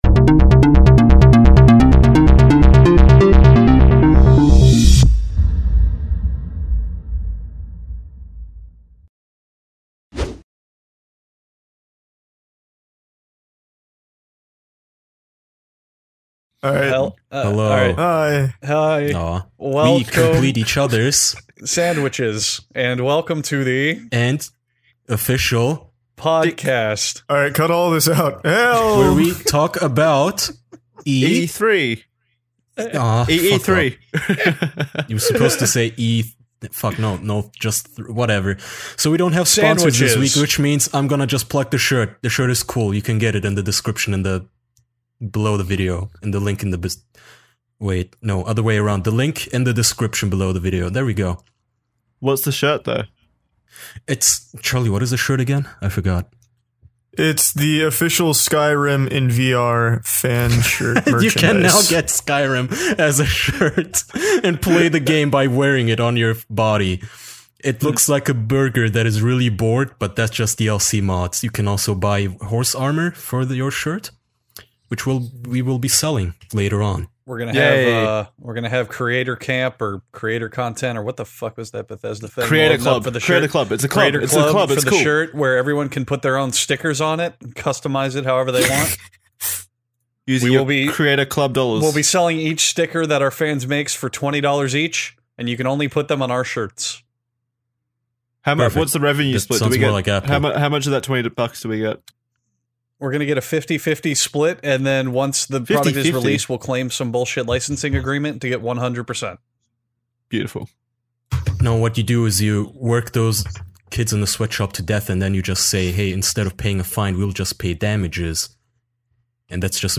Four close man friends gather around to discuss E3 2017.